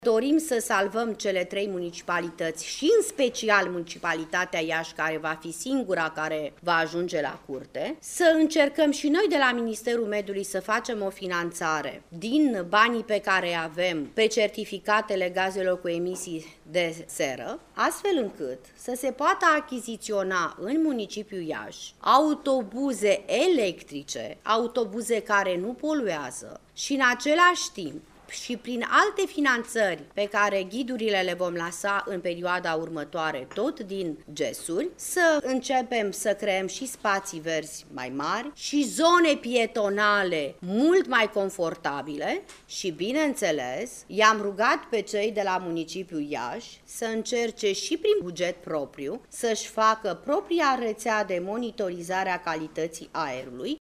Cu prioritate, autobuzele electrice vor putea fi achiziţionate de primăriile din Bucureşti, Braşov şi Iaşi, orașe pentru care comisia europeană a declanşat procedura de infrigement pe mediu, a declarat, astăzi, la Iași, ministrul mediului, Graţiela Gavrilescu: